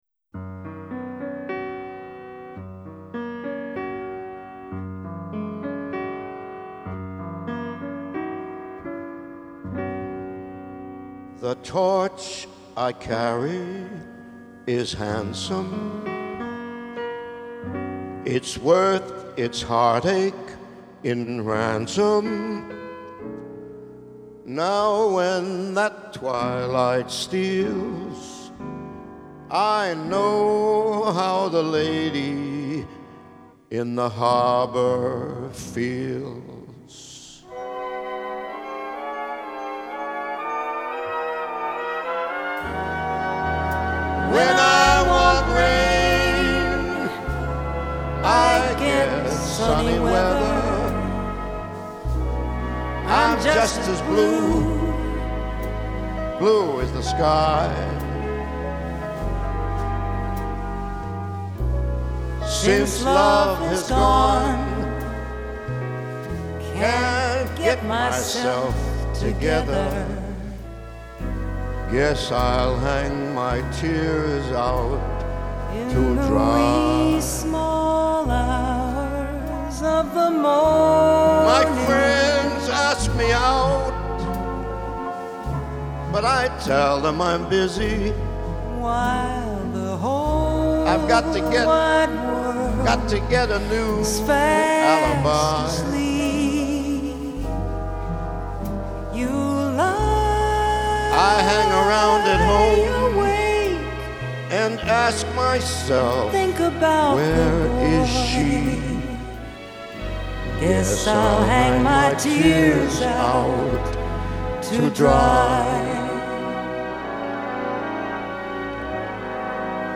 Duet medley